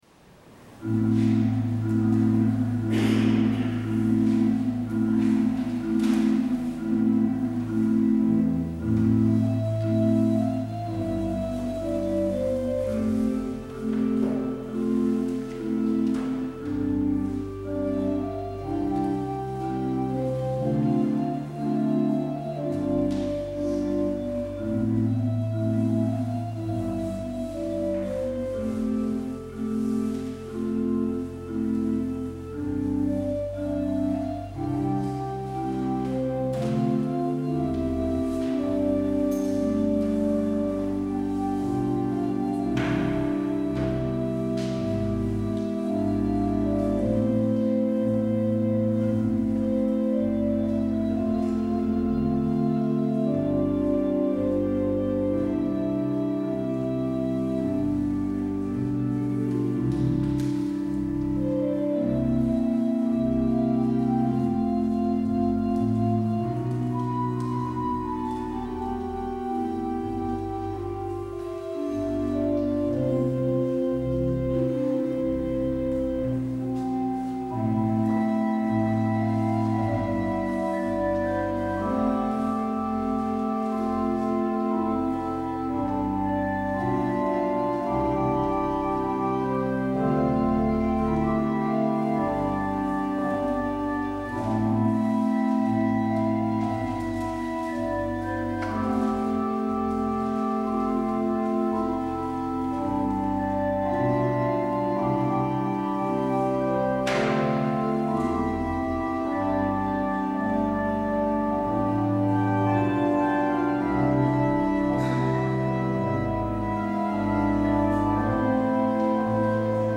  Luister deze kerkdienst terug: Alle-Dag-Kerk 12 april 2022 Alle-Dag-Kerk https
Het openingslied is Psalm 2 : 1 en 4 – Wat drijft de volken, wat bezielt ze toch?